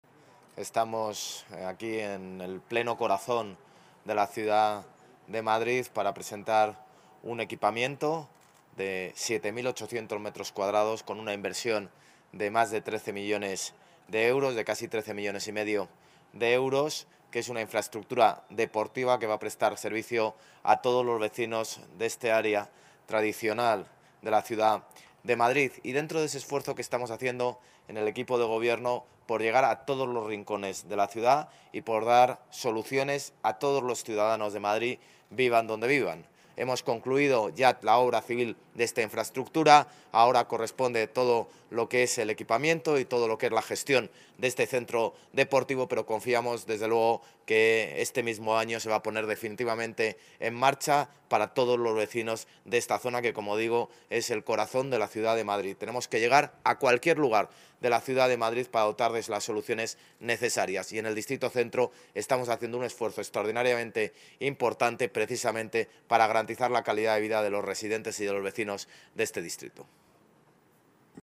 José Luis Martínez-Almeida, alcalde de Madrid